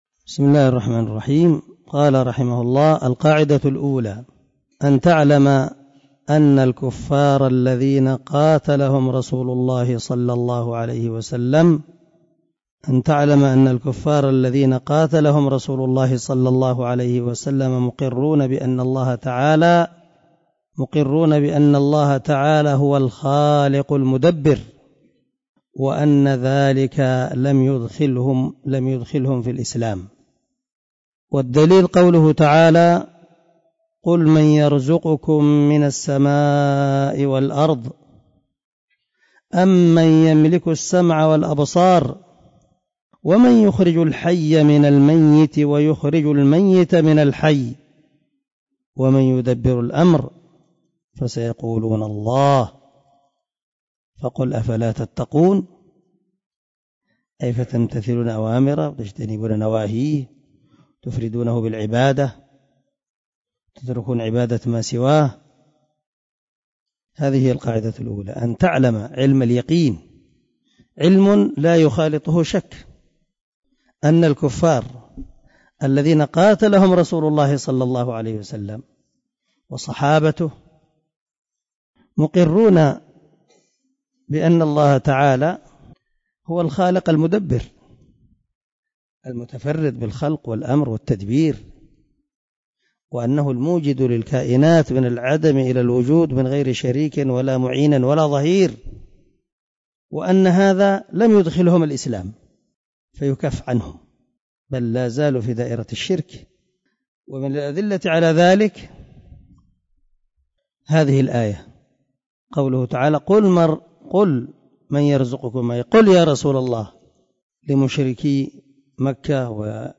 الدرس 3 القاعدة الأولى من شرح القواعد الأربع